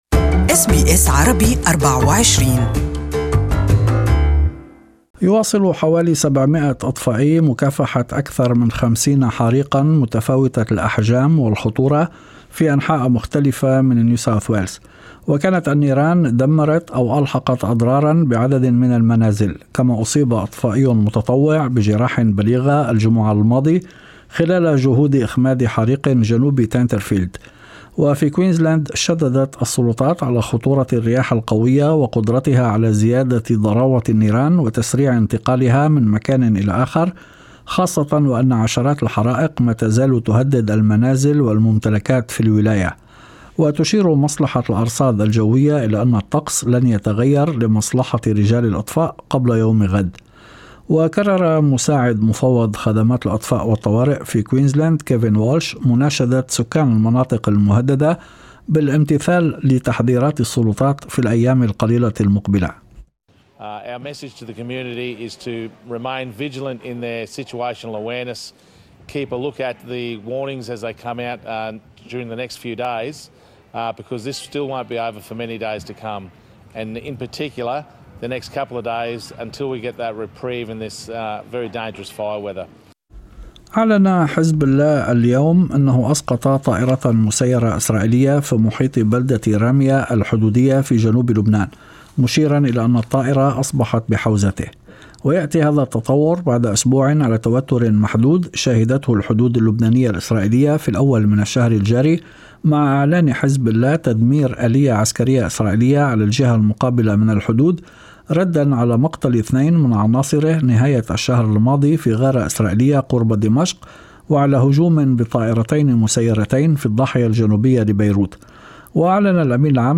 Evening News: NSW blazes as fire crews prepare for long fight